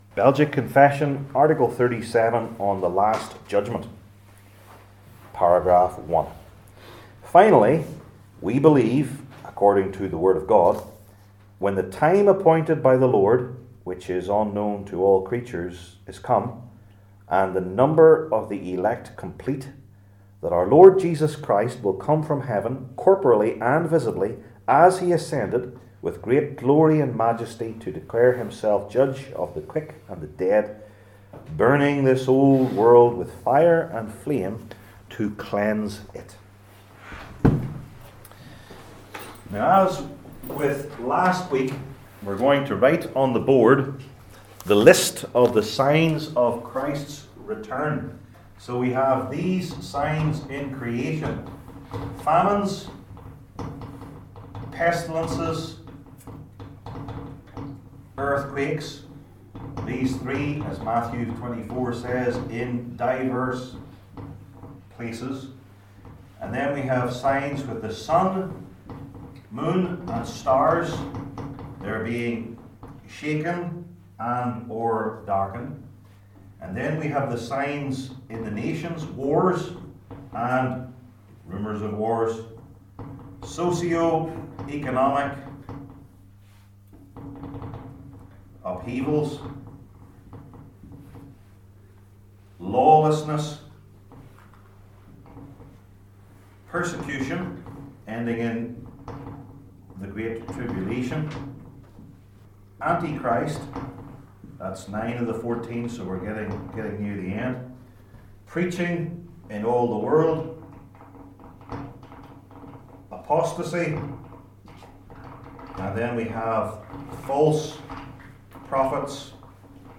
Series: Belgic Confession 37 , The Last Judgment Passage: John 12:23-43 Service Type: Belgic Confession Classes